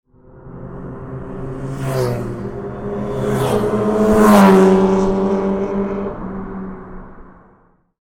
Speeding Motorcycles On Road Sound Effect
Several motorcycles race rapidly through the street, filling the air with roaring engines and a rushing whoosh. This high-speed motorcycle sound effect captures the power, speed, and excitement of multiple bikes passing by.
Speeding-motorcycles-on-road-sound-effect.mp3